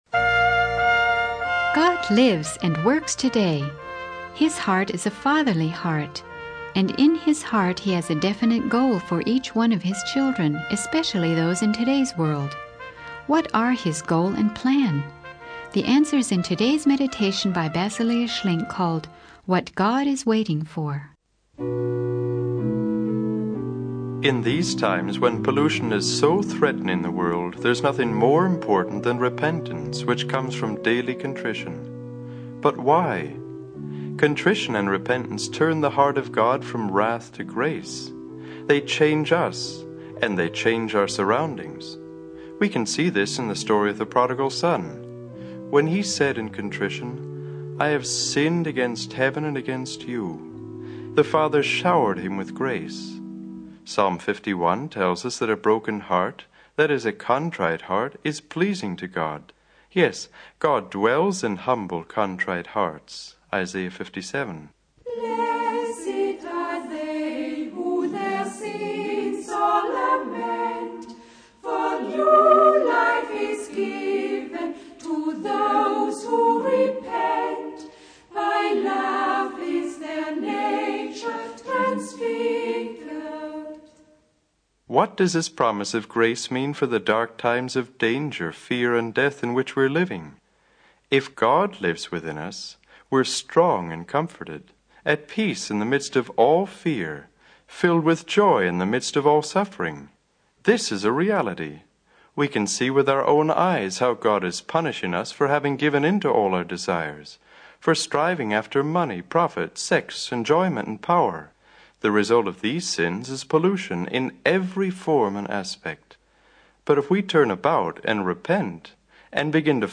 The sermon transcript discusses the importance of repentance and contrition in the eyes of God.